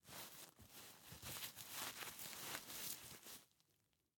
take_diaper.ogg